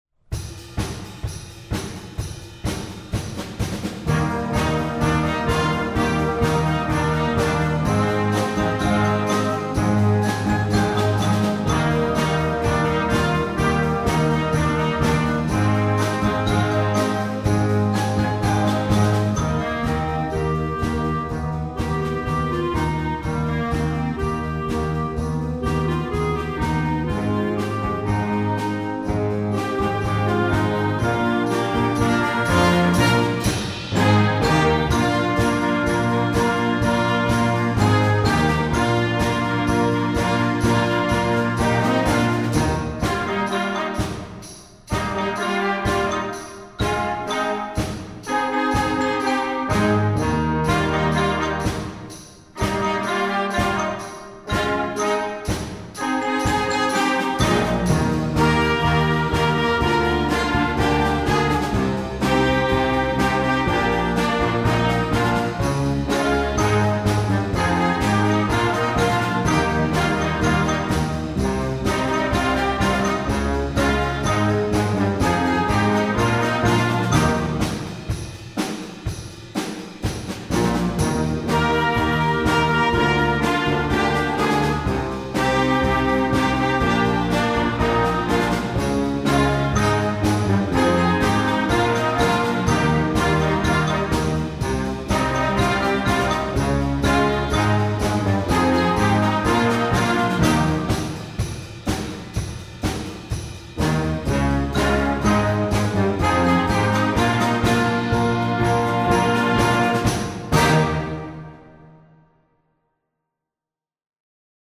Werk für Jugendblasorchester
Besetzung: Blasorchester